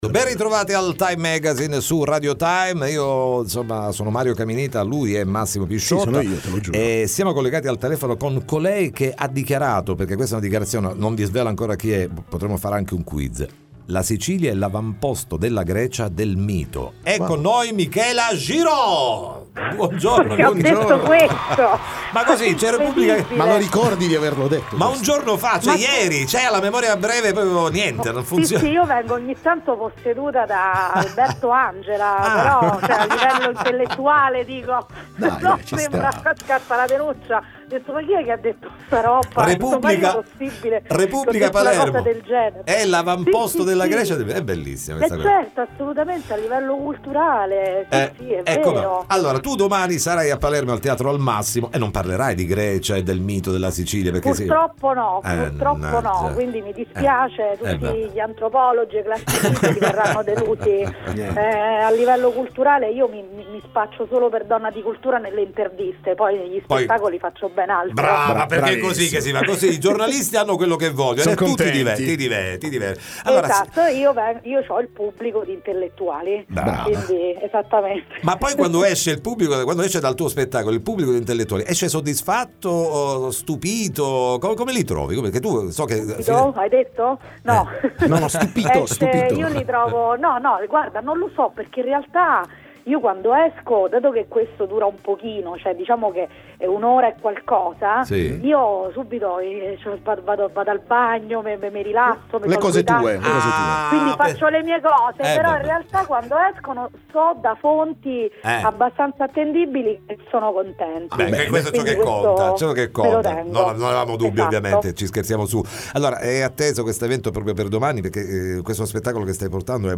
Mi hanno gettata in mezzo ai lupi: il nuovo spettacolo di Michela Giraud, ne parliamo con lei in collegamento telefonico